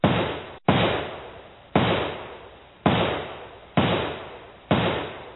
Silencer
The sound of a gun silencer being fired 2x at someone.